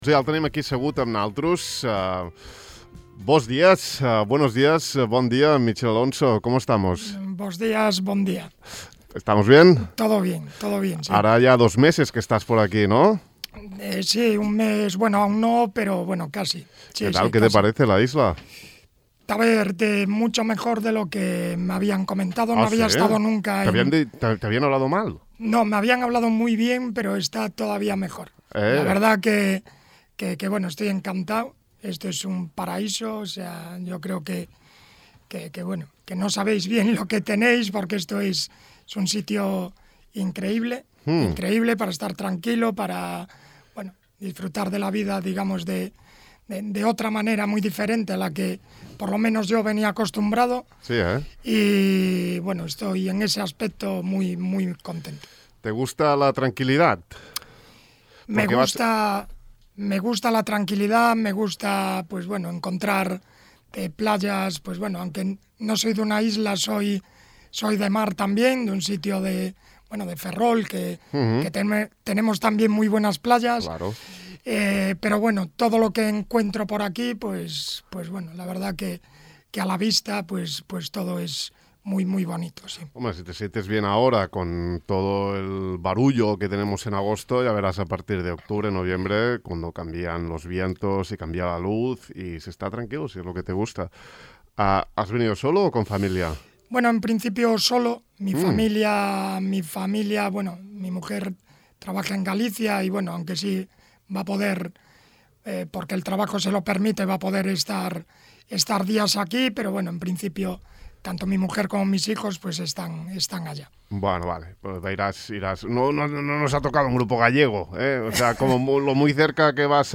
Ahir el vam convidar a Ràdio Illa, on ens va explicar es seves sensacions sobre el club i l’illa, mes i mig de desembarcar aquí. També vam analitzar la plantilla i el grup on jugarà enguany el Formentera.